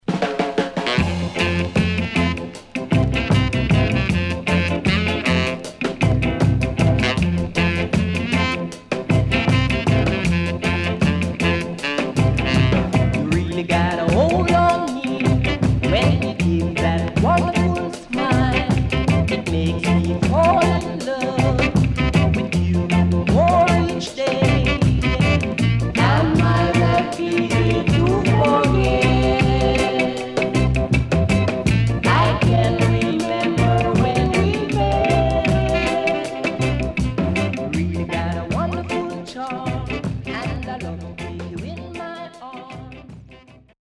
HOME > REISSUE [SKA / ROCKSTEADY]
Rare Great Rocksteady Vocal